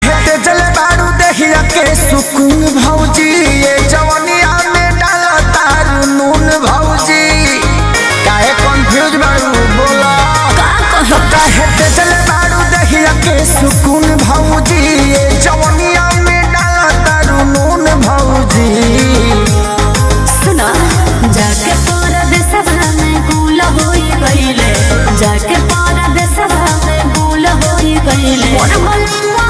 Bhojpuri Songs